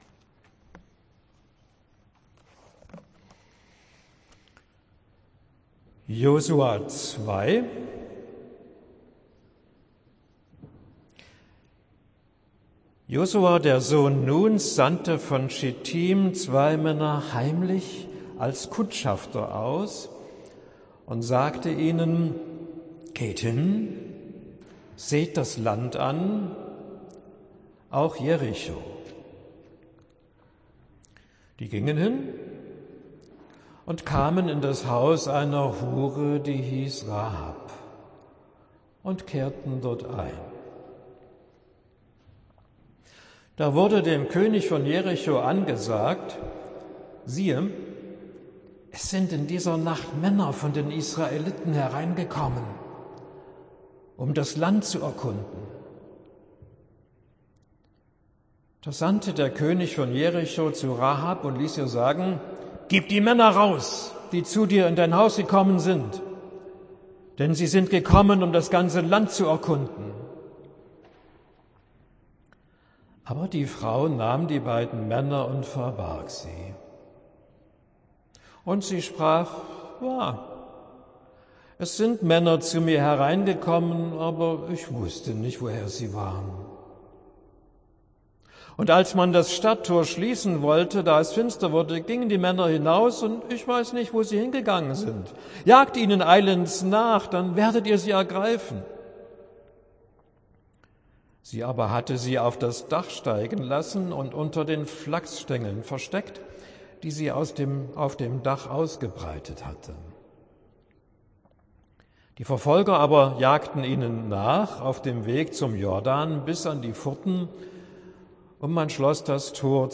Predigt für den 17. Sonntag nach TrinitatisSie können das Manuskript mit zahlreichen Anmerkungen HIER NACHLESEN!